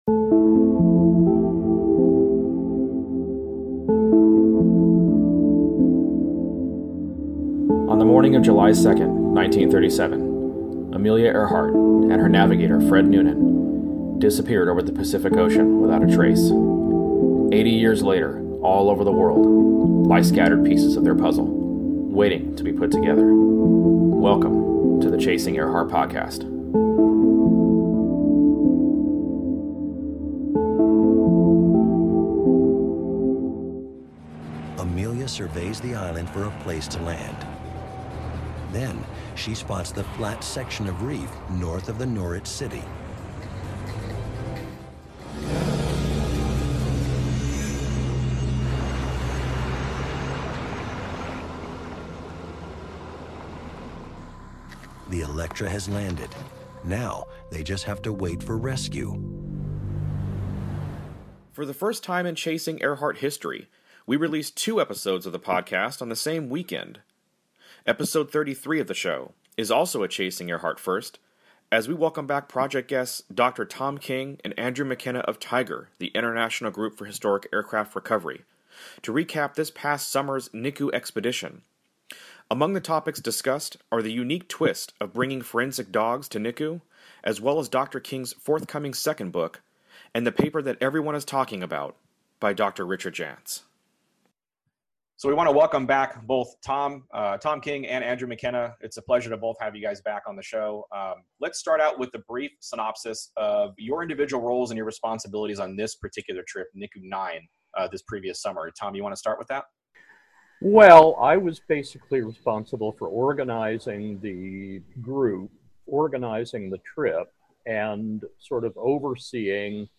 Niku IX Recap: A Conversation